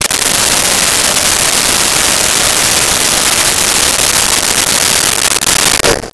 Blow Dryer 01
Blow Dryer 01.wav